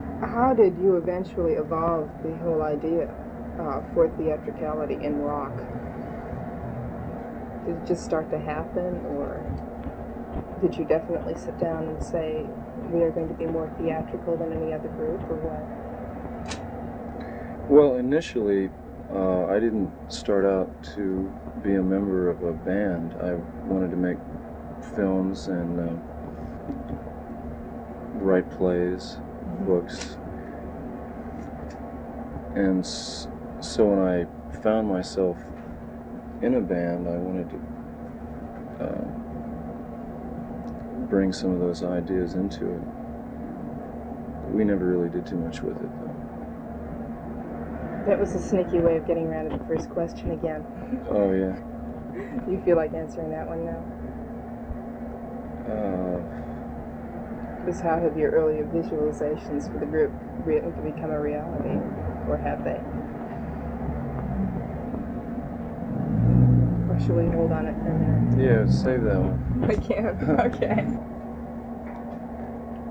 09 The Idea for Theatricality in Rock (The Lost Interview Tapes - Volume Two).flac